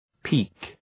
Προφορά
{pi:k}